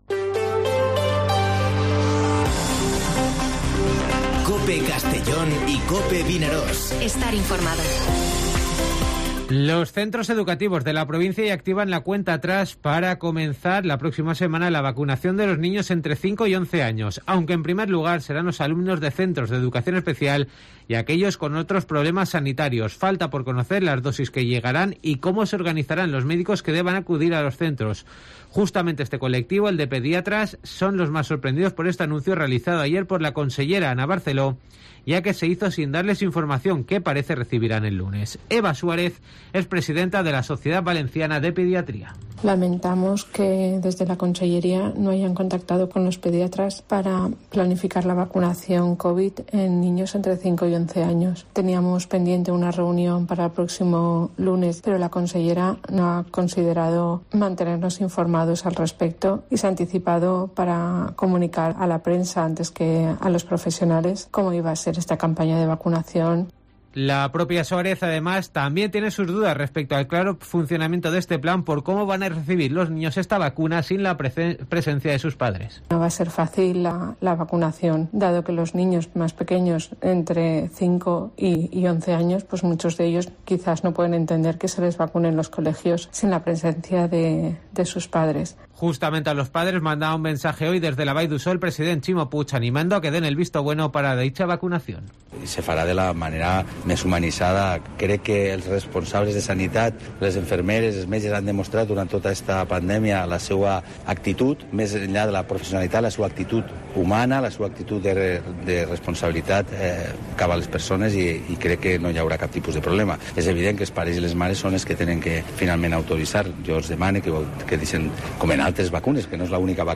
Informativo Mediodía COPE en Castellón (10/12/2021)